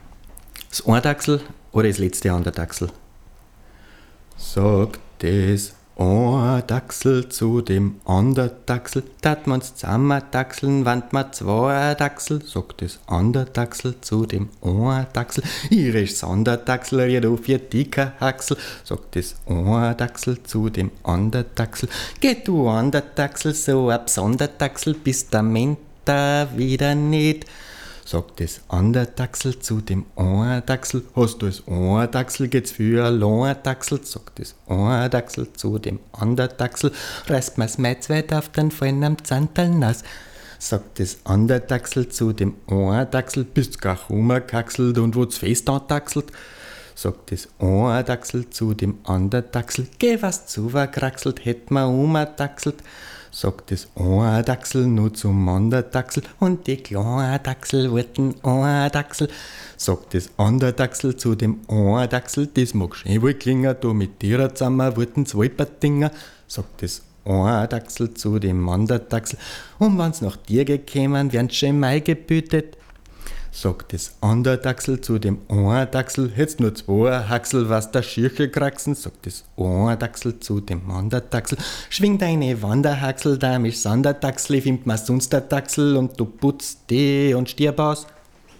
♫ oadaxl - akustik-skizze ♫
oadaxl-MXL-v69-PhlpsMiniwatt.mp3